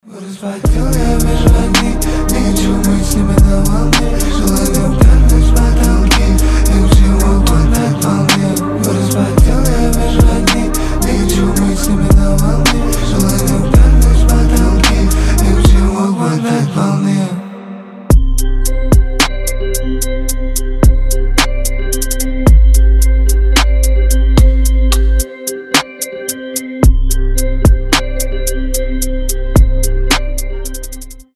рэп , trap , атмосферные